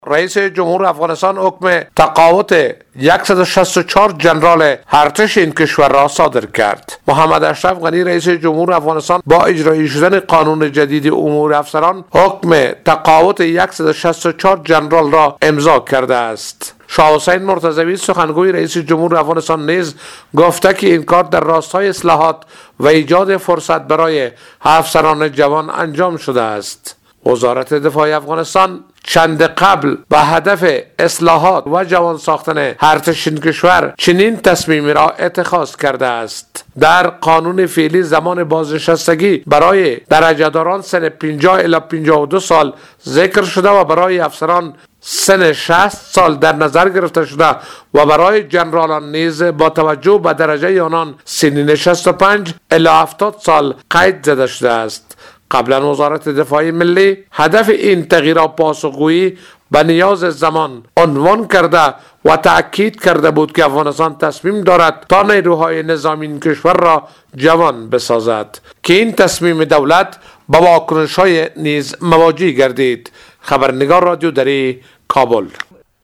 جزئیات بیشتر در گزارش همکارمان